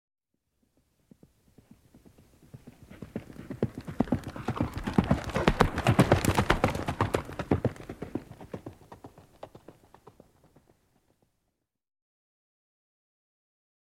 جلوه های صوتی
دانلود صدای اسب 18 از ساعد نیوز با لینک مستقیم و کیفیت بالا